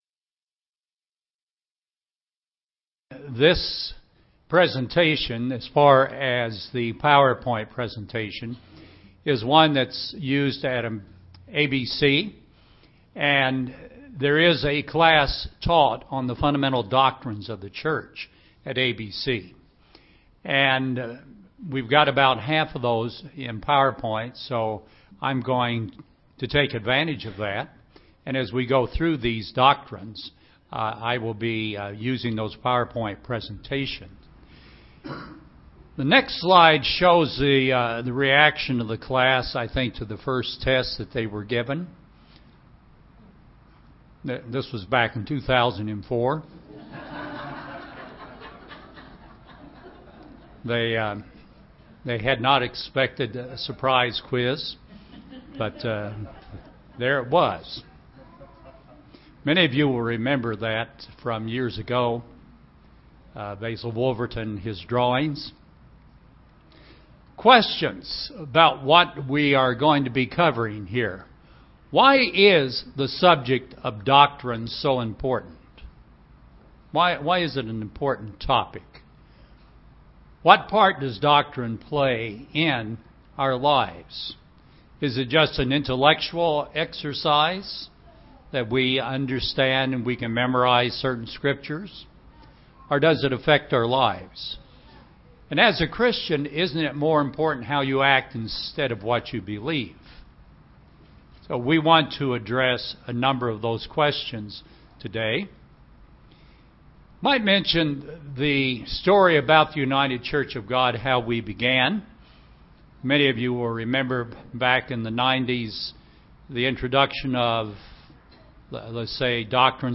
Bible study on the doctrines and fundamental beliefs of UCGIA